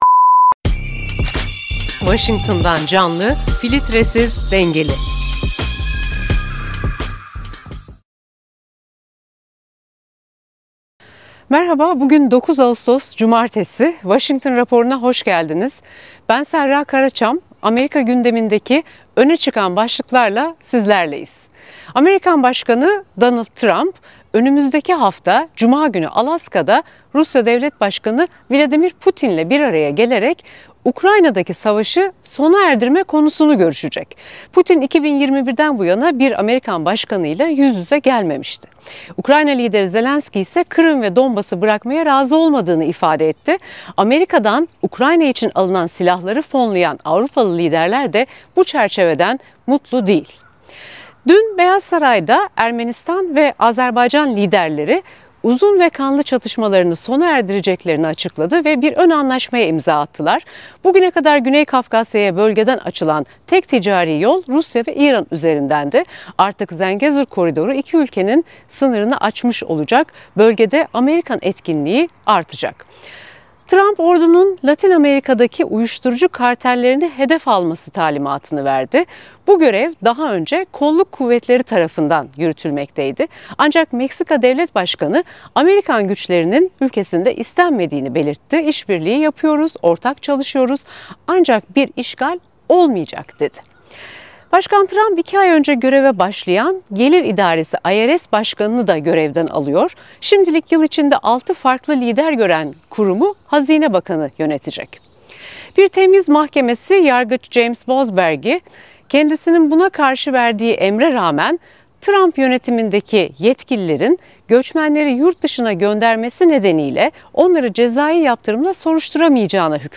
Genres: News, News Commentary, Politics